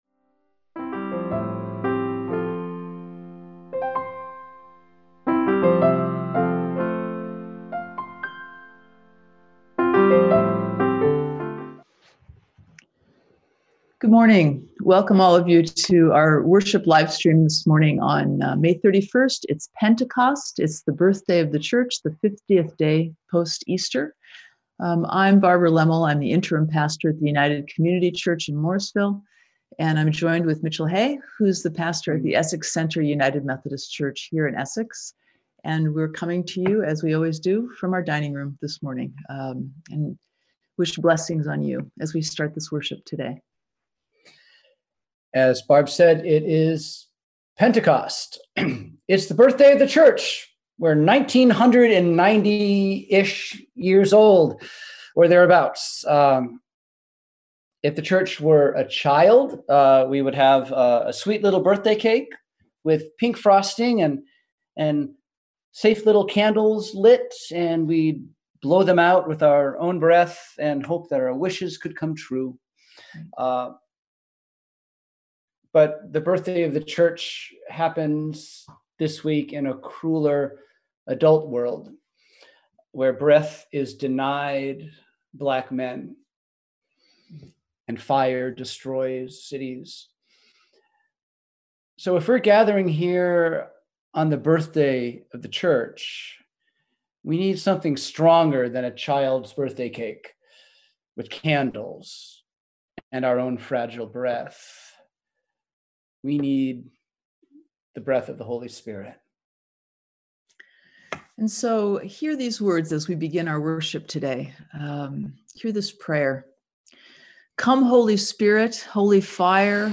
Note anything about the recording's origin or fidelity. We held virtual worship on Sunday, May 31, 2020 at 10am!